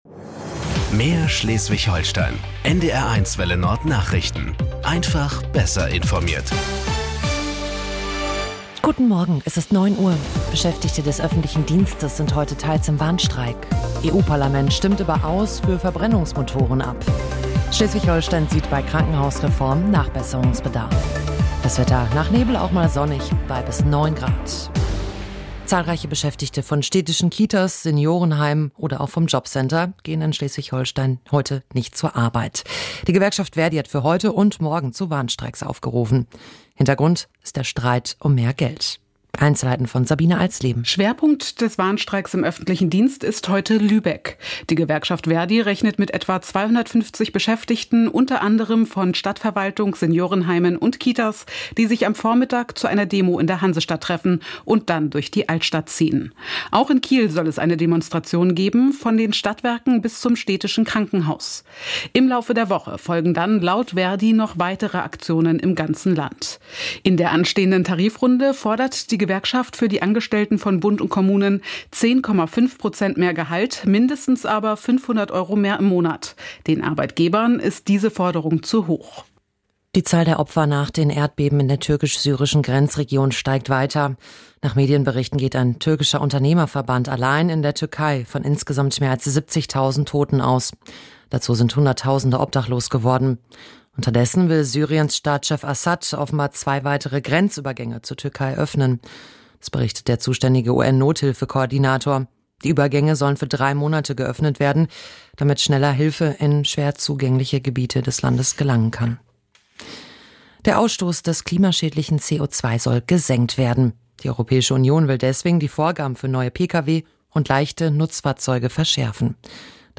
Nachrichten 16:00 Uhr - 14.02.2023